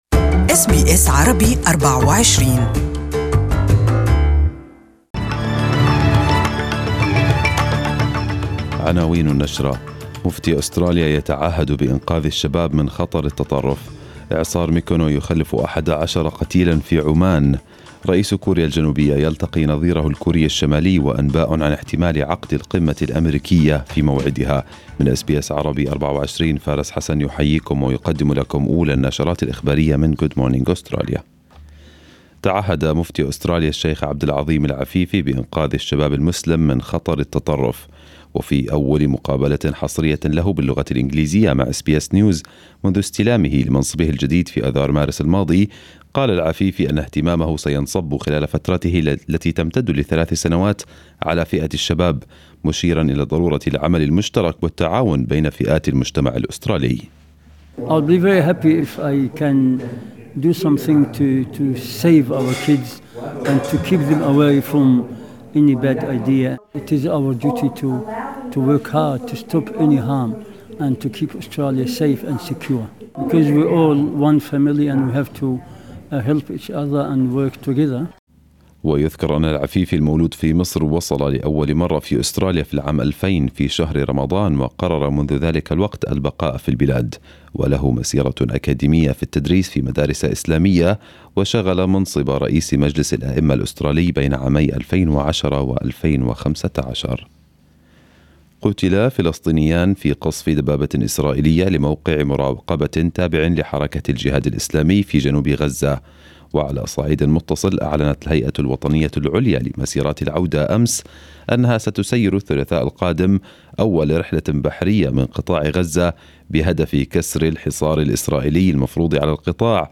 Arabic News Bulletin